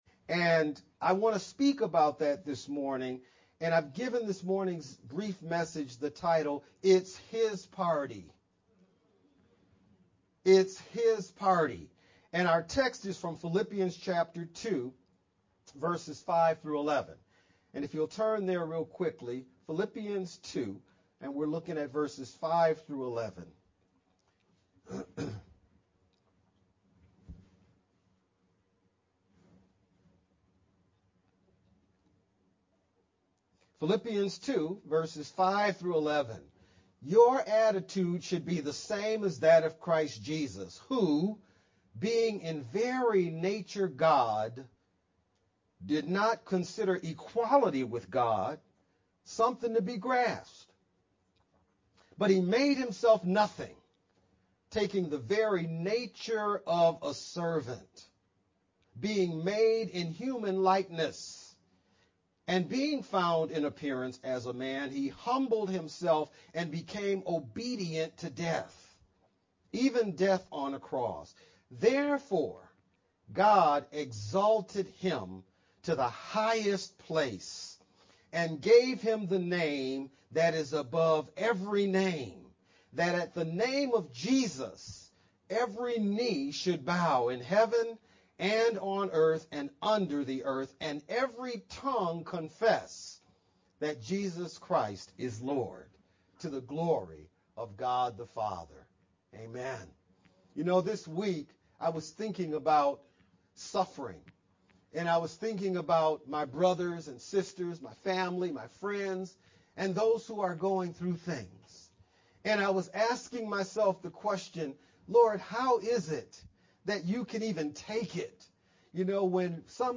March-12th-VBCC-edited-sermon-only-Mp3-CD.mp3